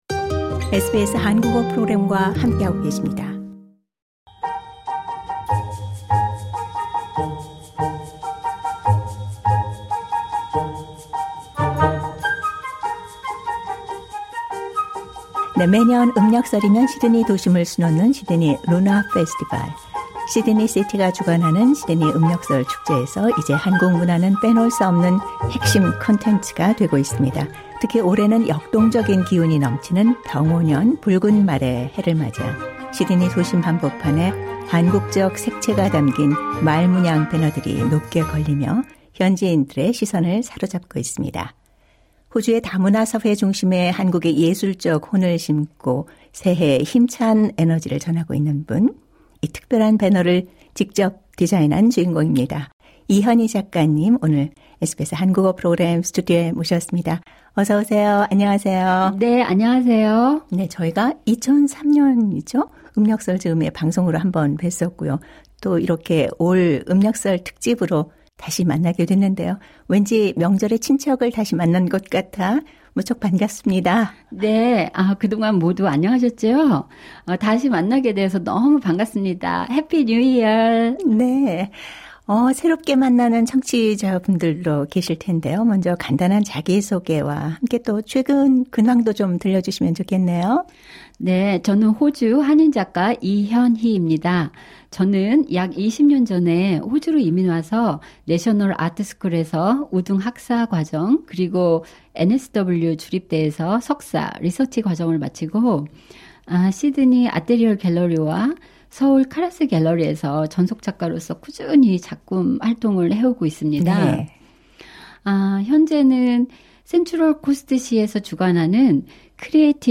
상단의 팟캐스트를 통해 인터뷰 전체 내용을 들으실 수 있습니다.